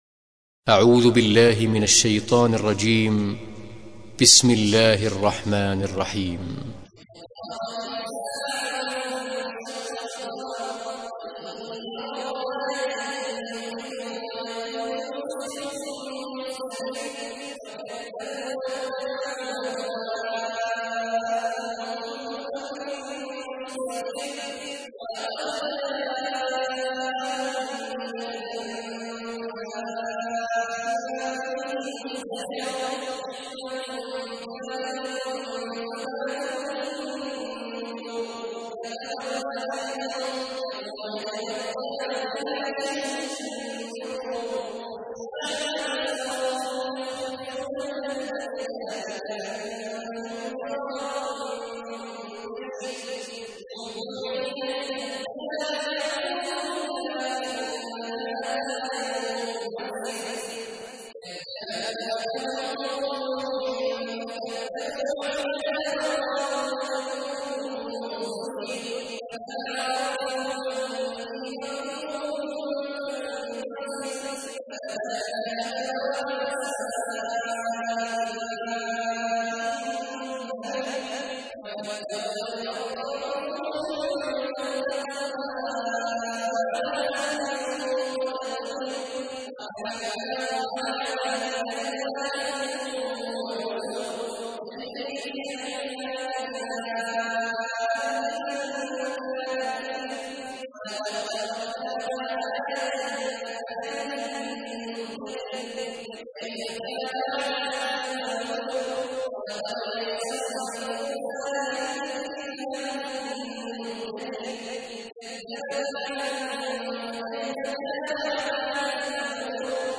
تحميل : 54. سورة القمر / القارئ عبد الله عواد الجهني / القرآن الكريم / موقع يا حسين